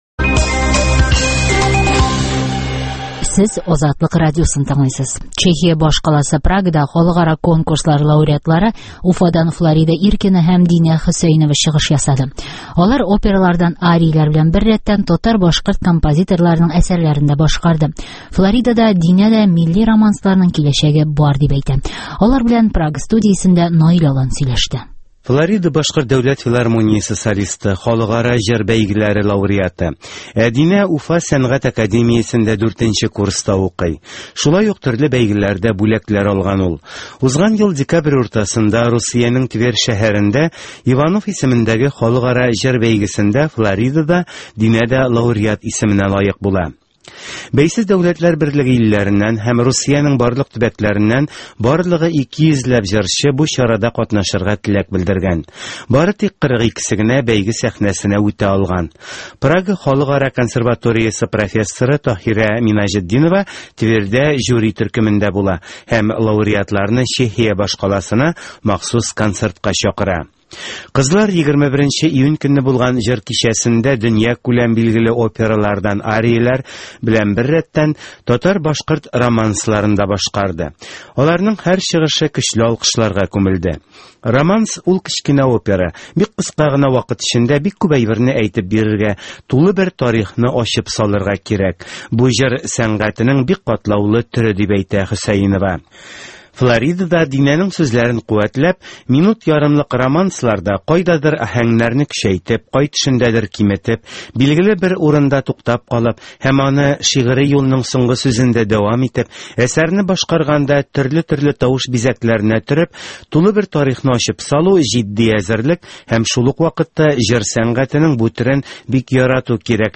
Прагада татар-башкорт романслары яңгырады